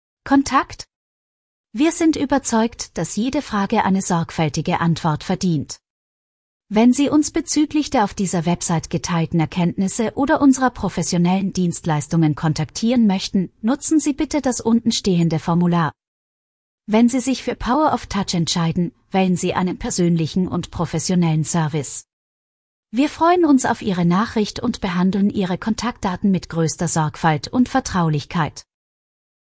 mp3-text-to-voice-kontakt-power-of-touch.mp3